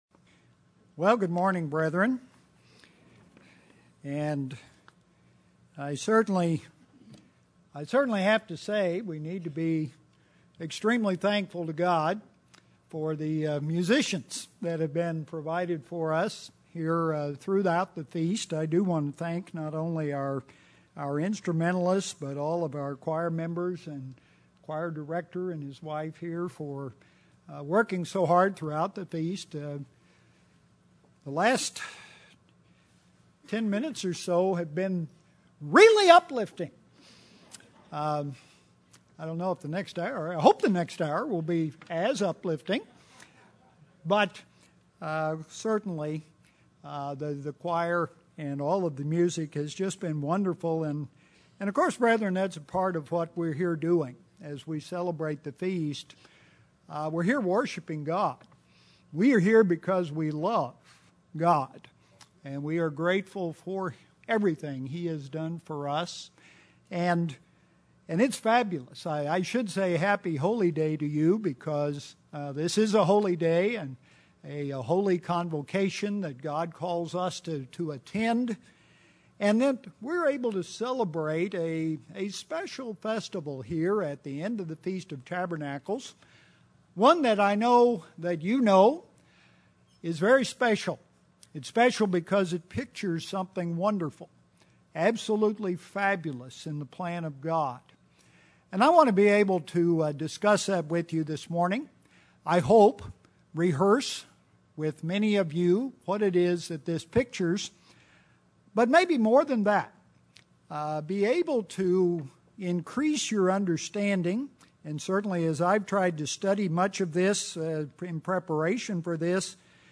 This sermon was given at the Branson, Missouri 2013 Feast site.